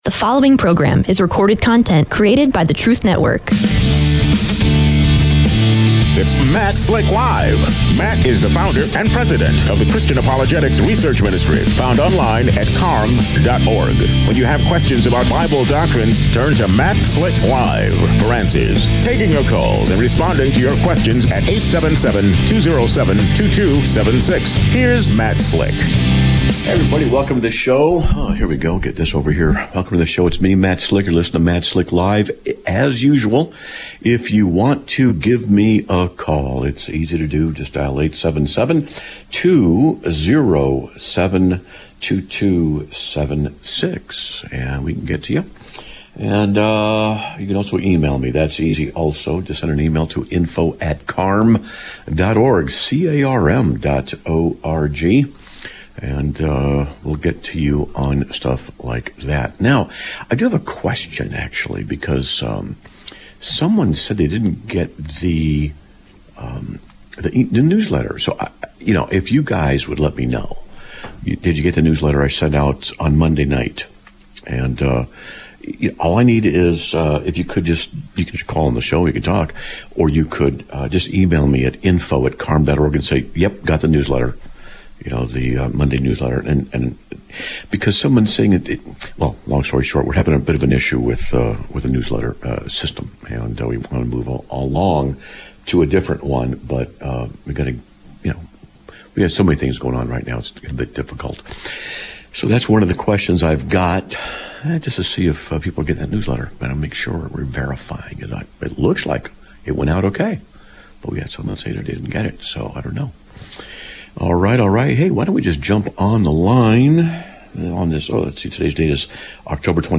Live Broadcast of 10/22/2025